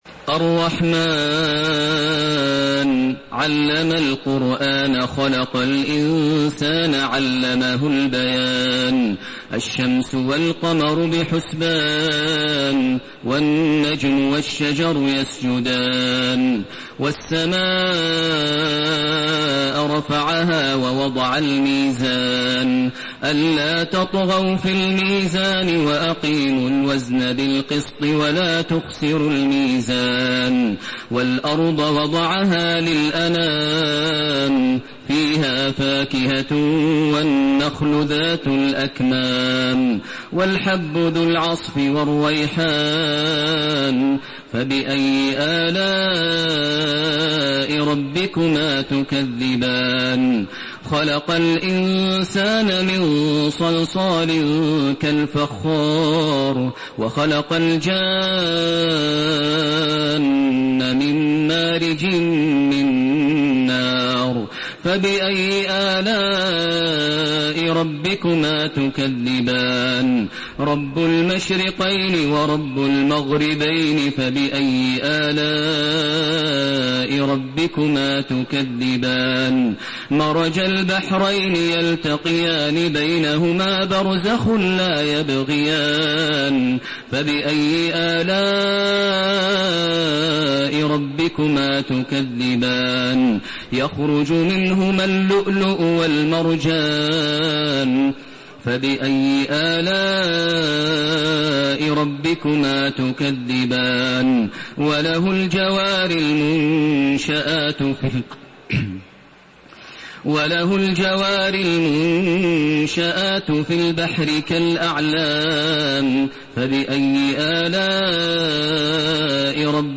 Surah Ar-Rahman MP3 by Makkah Taraweeh 1432 in Hafs An Asim narration.
Murattal Hafs An Asim